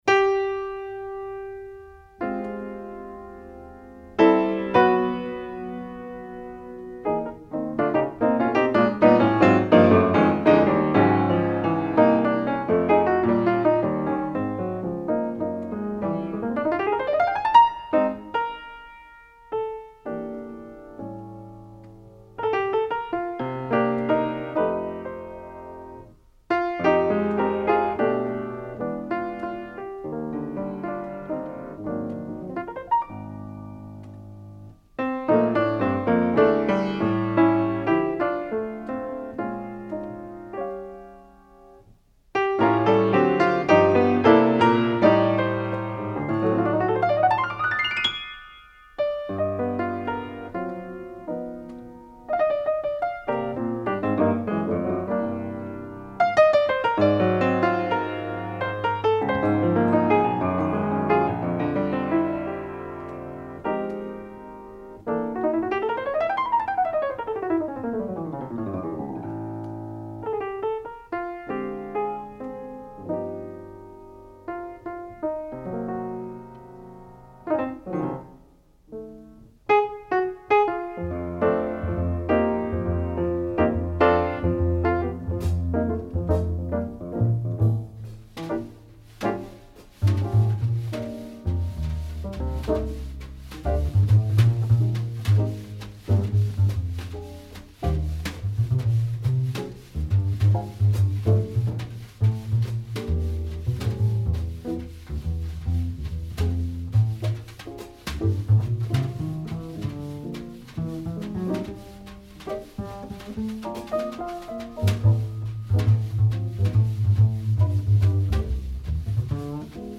Mid 60's Spanish jazz